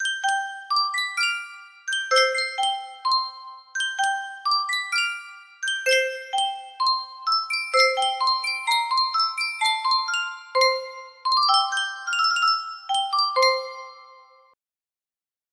Sankyo Music Box - His Eye Is on the Sparrow CAL music box melody
Full range 60